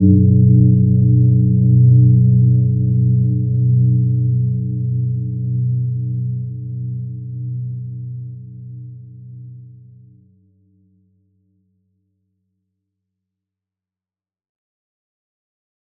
Gentle-Metallic-2-B2-p.wav